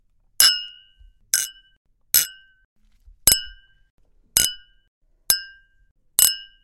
派对之声 " BRINDE
描述：som de brinde，taçassendoencostadas